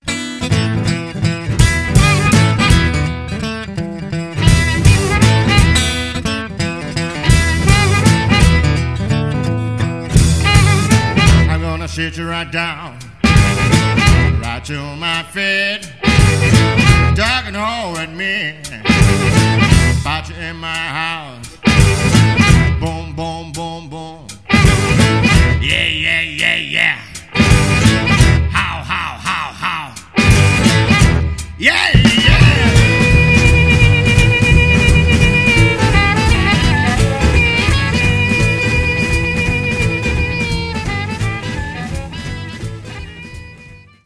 lead vocal, acoustic guitar, dobro, slide
acoustic guitar, 12-string-guitar, vocal
harmonica
drums
double bass